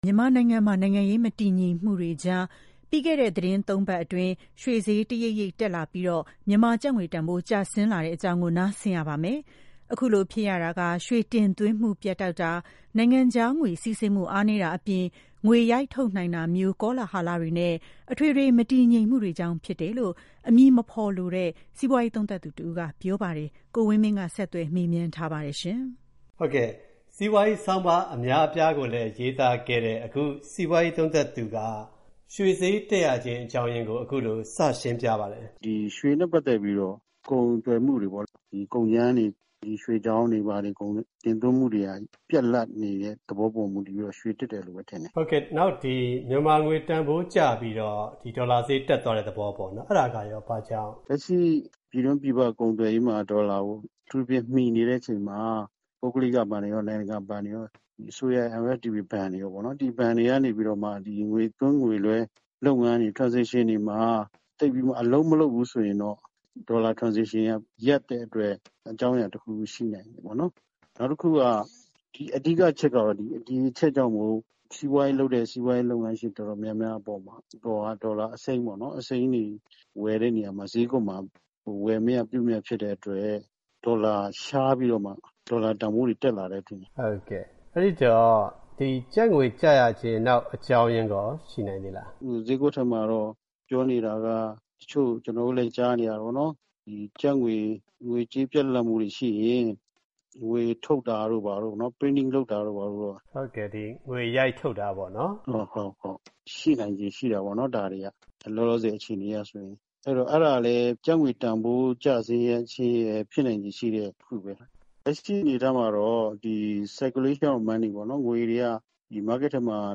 ရွှေဈေး ဒေါ်လာဈေး တက်တဲ့ကိစ္စ ဂယက်ရိုက်ခတ်မှု ကြီးမားနိုင် (စီးပွားရေးဆောင်းပါးရှင်နှင့် မေးမြန်းချက်)